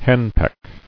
[hen·peck]